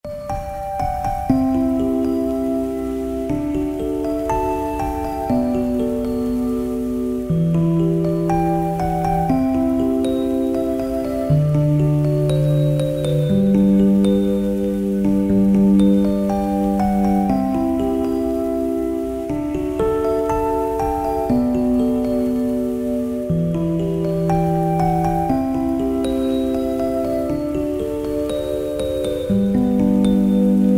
christmas-song.mp3